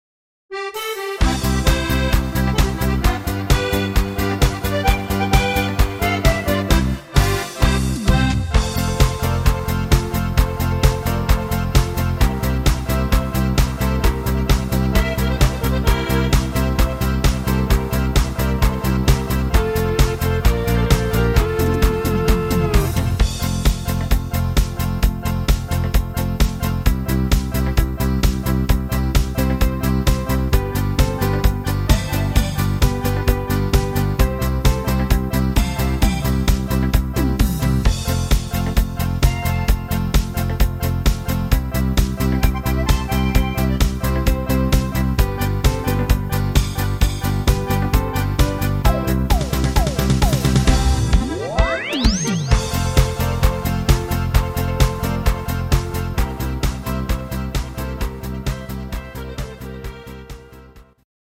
guter Stimmungshit